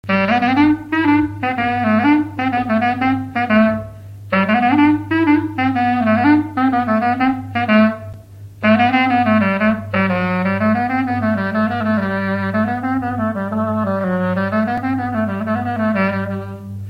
instrumental
danse-jeu : quadrille : trompeuse
Pièce musicale inédite